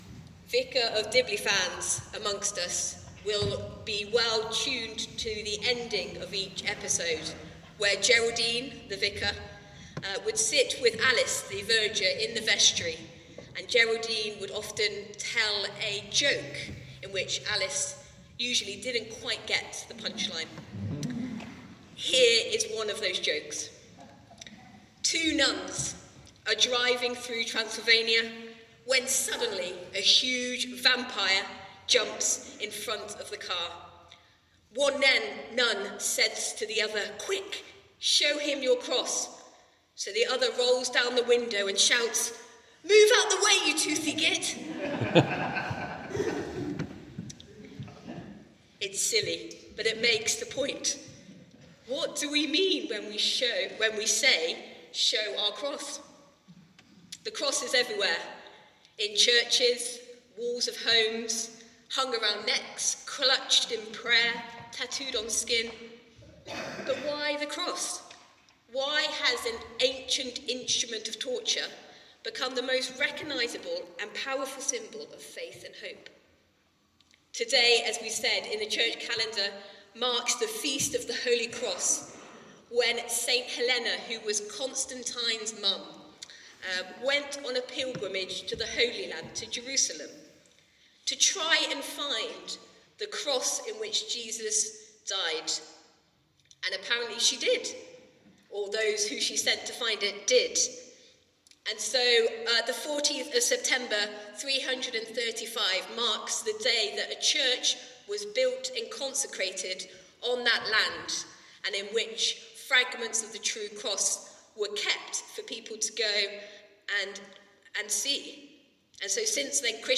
Sermon and Readings for Sunday 14th September 2025